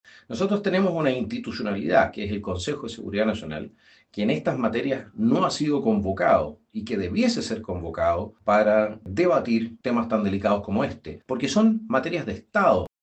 Así, en voz de su presidente, Johannes Kaiser, solicitaron convocar al Consejo de Seguridad Nacional para analizar las implicancias estratégicas del proyecto y la tensión diplomática, cuestionando además el carácter restrictivo de la instancia.